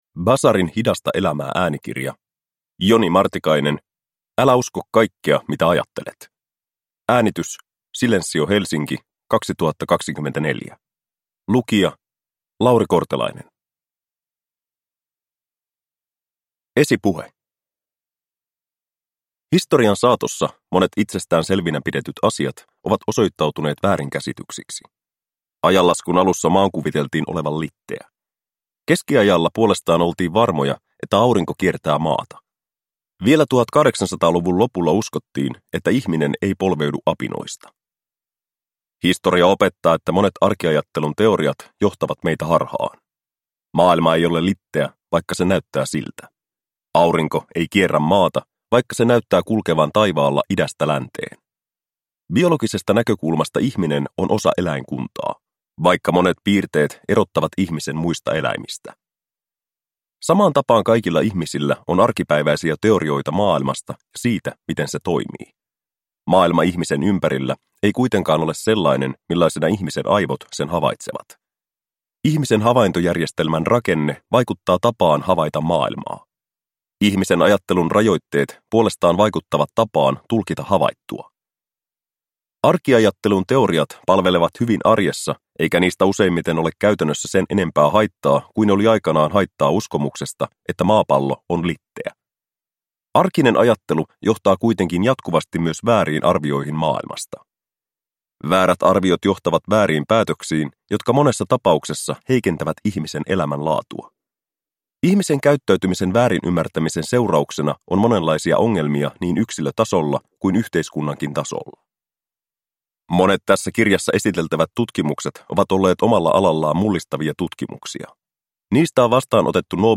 Älä usko kaikkea mitä ajattelet – Ljudbok